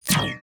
UIClick_Menu Negative Laser Shot 01.wav